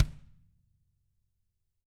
Index of /90_sSampleCDs/ILIO - Double Platinum Drums 1/CD4/Partition A/TAMA KICK D